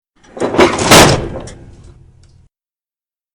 Single Industrial Breaker Throw
SFX
yt_fcKQ1GRPCB8_single_industrial_breaker_throw.mp3